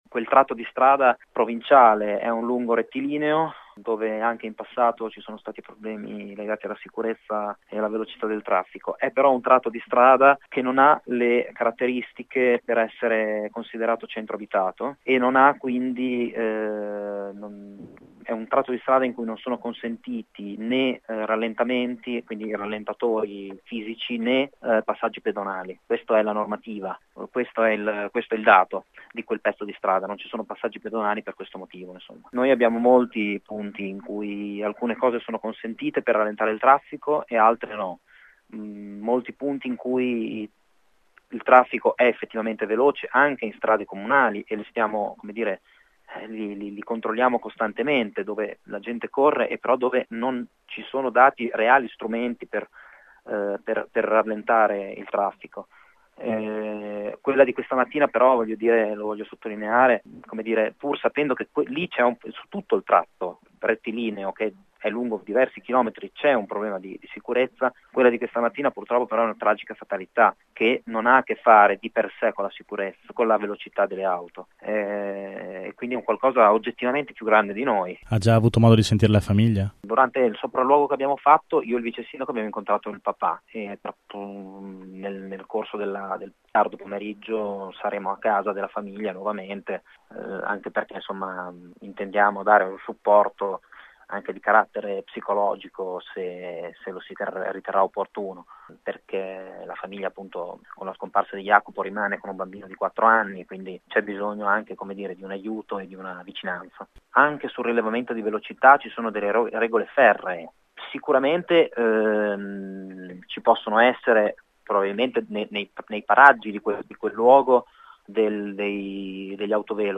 Parla di “tragica fatalità” il sindaco di Budrio, Giulio Pierini.